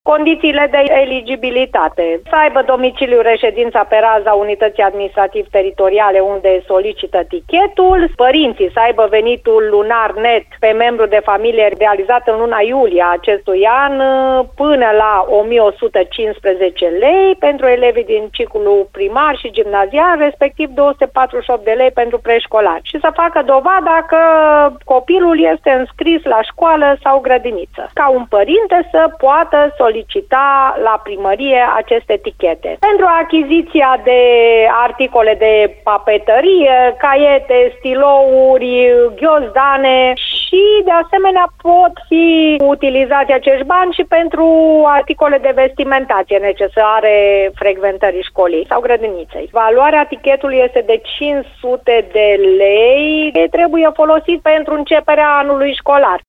Părinții elevilor defavorizaţi care au dreptul să primească cei 500 de lei pe anul școlar în curs trebuie să îndeplinească mai multe condiții de eligibilitate, spune prefectul de Timiș, Liliana Oneț.